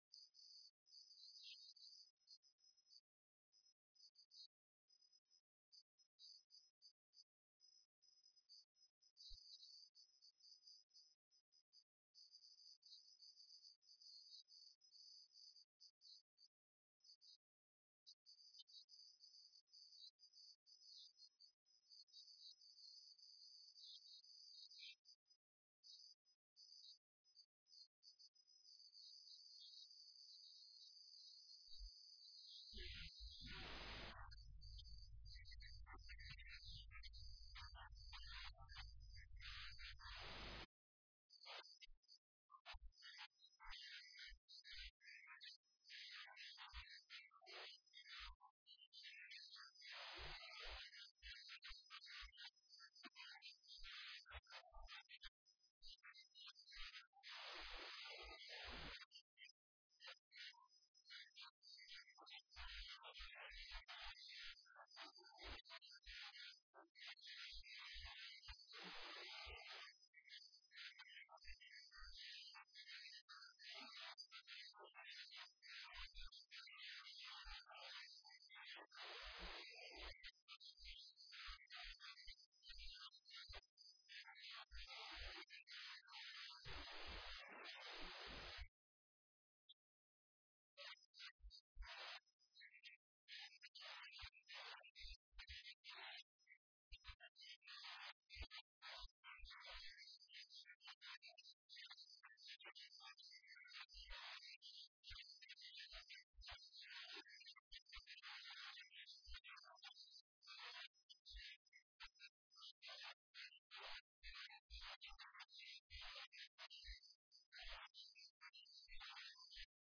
Dhageyso; Warka Subax ee Radio Muqdisho